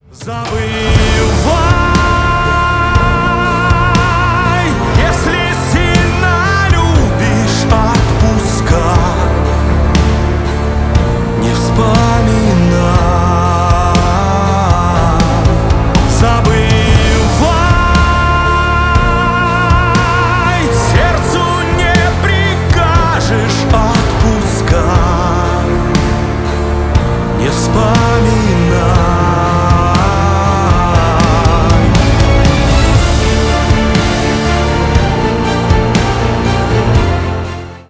бесплатный рингтон в виде самого яркого фрагмента из песни